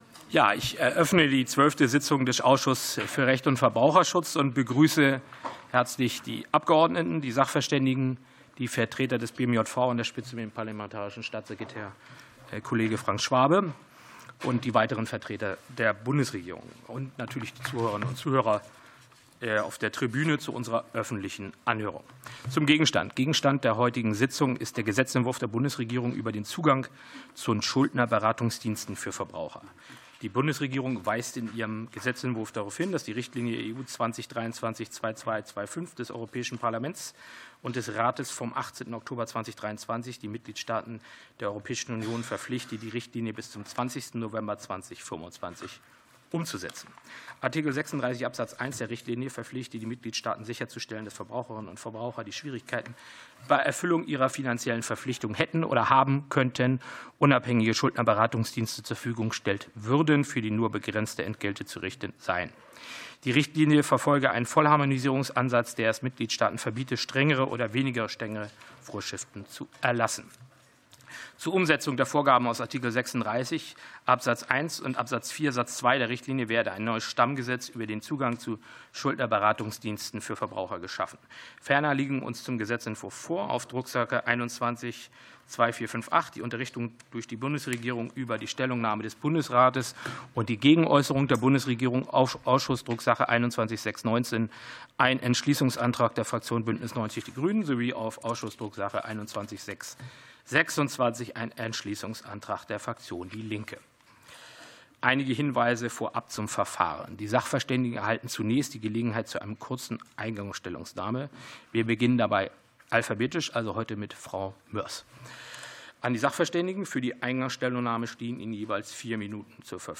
Anhörung des Ausschusses für Recht und Verbraucherschutz